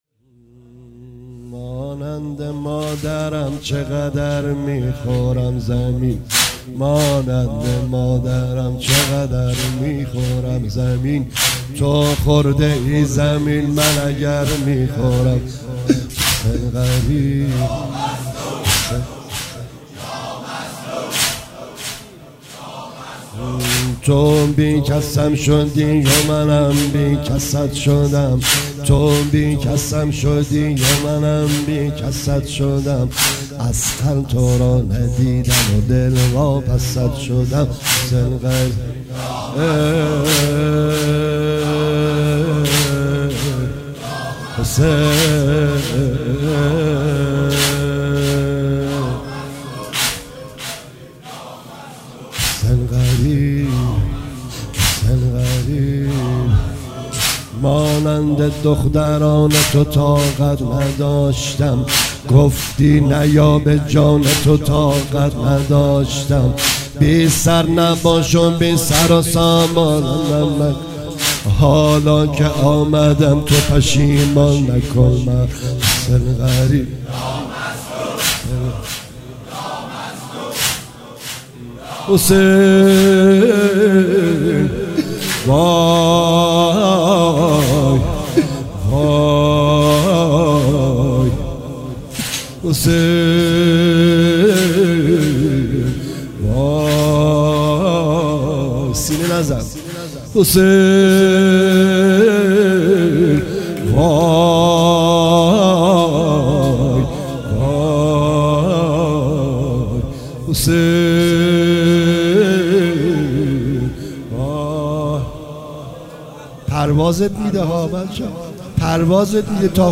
مداحی واحد تند شلاقی